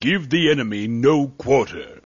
角色语音